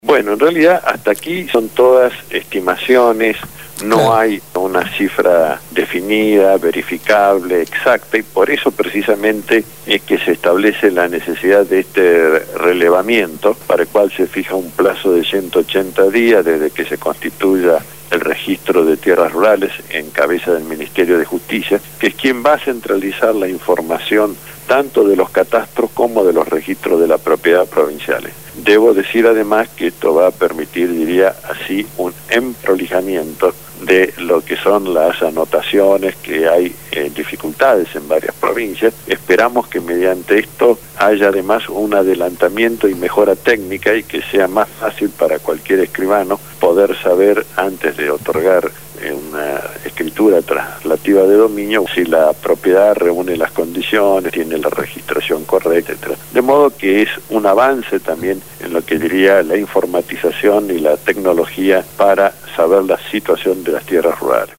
ABOGADO CONSTITUCIONALISTA